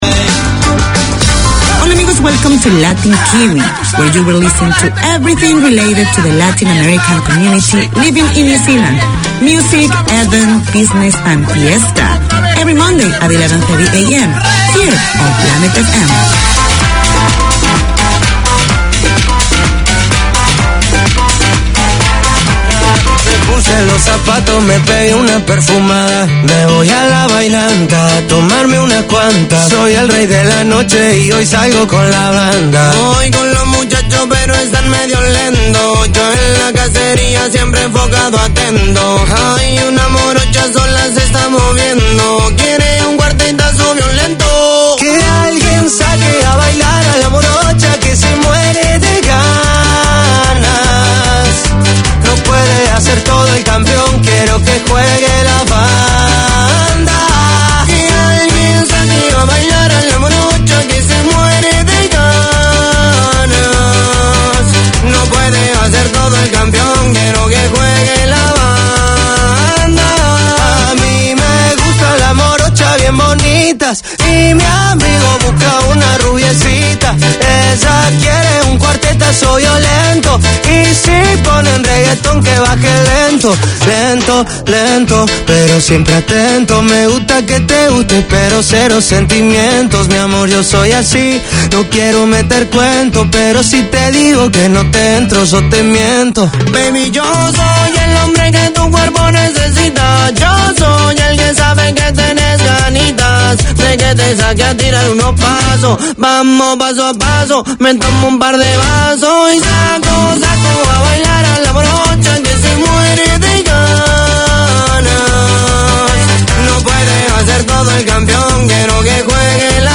Radio made by over 100 Aucklanders addressing the diverse cultures and interests in 35 languages.